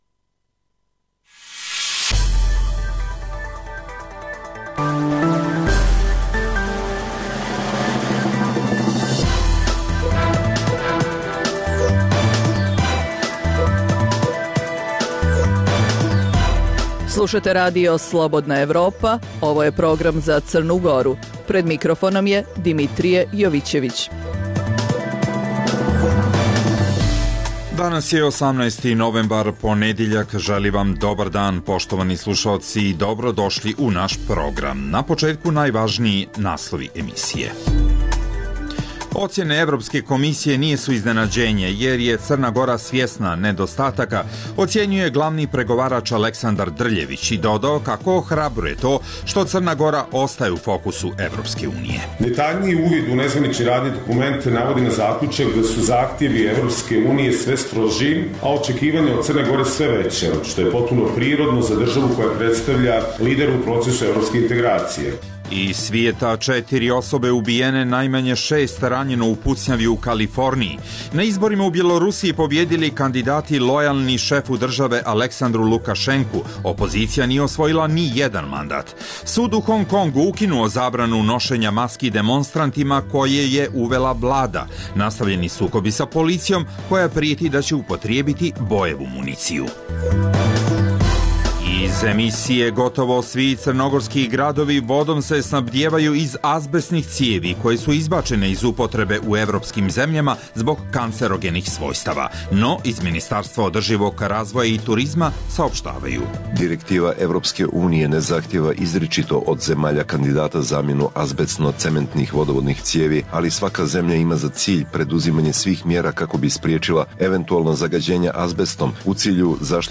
Emisija namijenjena slušaocima u Crnoj Gori. Sadrži lokalne, regionalne i vijesti iz svijeta, tematske priloge o aktuelnim dešavanjima iz oblasti politike, ekonomije i slično, te priče iz svakodnevnog života ljudi, kao i priloge iz svijeta.